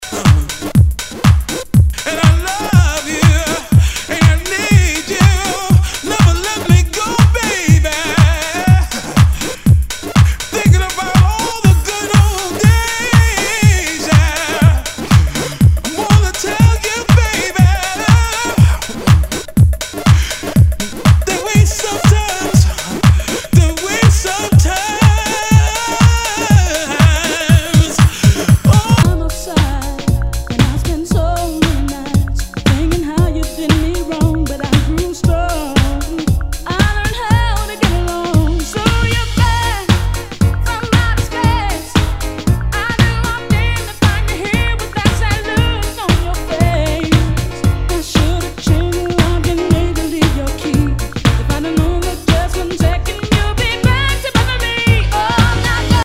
HOUSE/TECHNO/ELECTRO
ナイス！ヴォーカル・ハウス・クラシック！